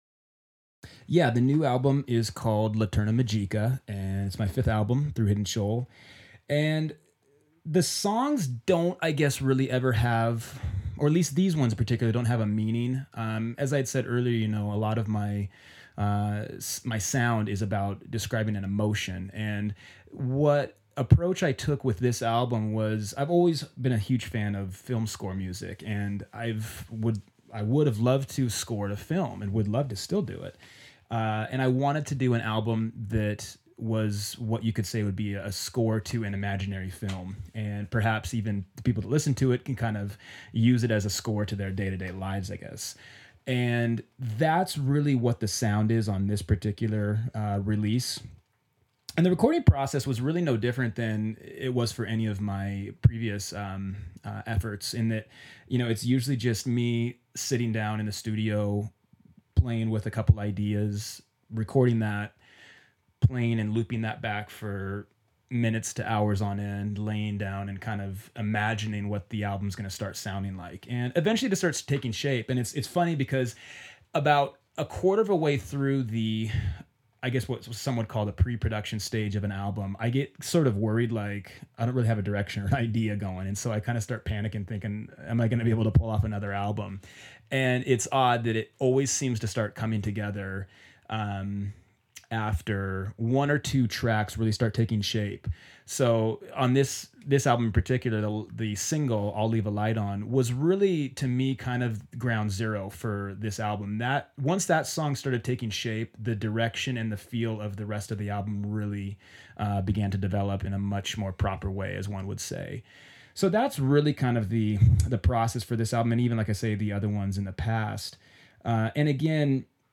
SLOW DANCING SOCIETY INTERVIEW – September 2012